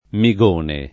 [ mi g1 ne ]